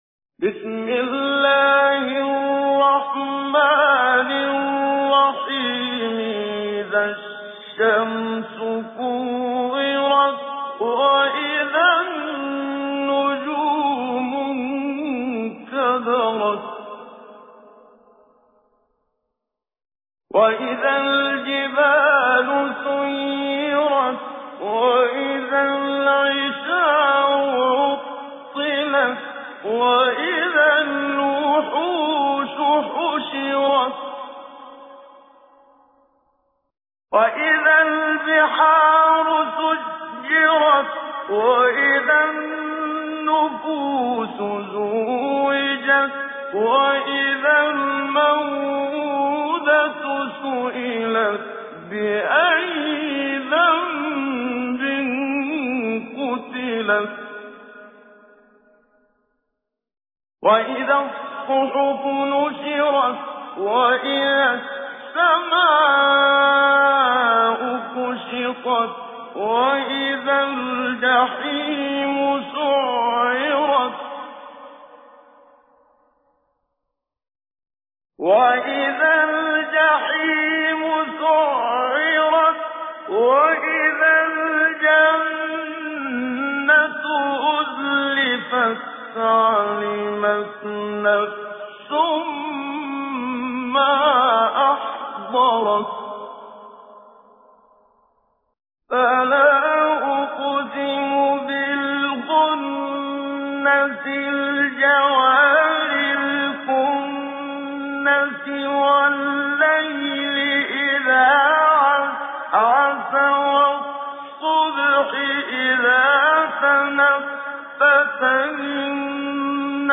تجويد
سورة التكوير الخطیب: المقريء الشيخ محمد صديق المنشاوي المدة الزمنية: 00:00:00